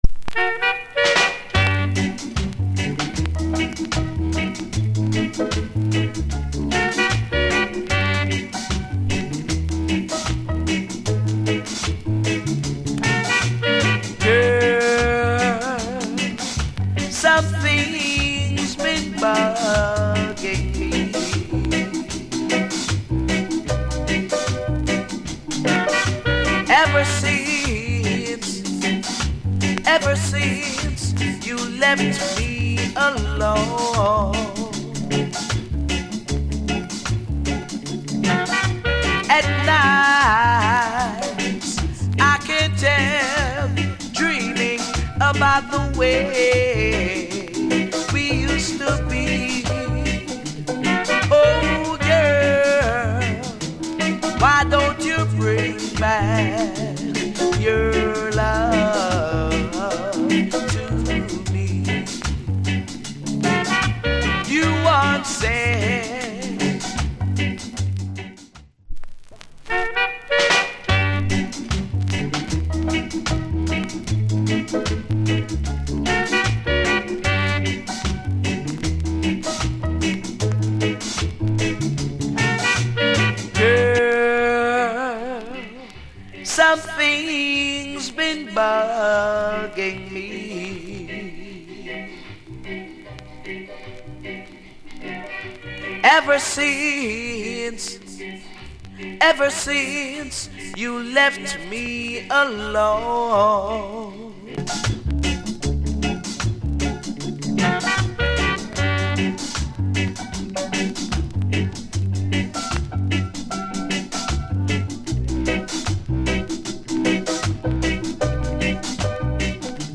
Male Vocal Condition VG+ Soundclip